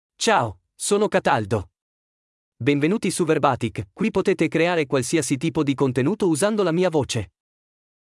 Cataldo — Male Italian (Italy) AI Voice | TTS, Voice Cloning & Video | Verbatik AI
Cataldo is a male AI voice for Italian (Italy).
Voice sample
Listen to Cataldo's male Italian voice.
Cataldo delivers clear pronunciation with authentic Italy Italian intonation, making your content sound professionally produced.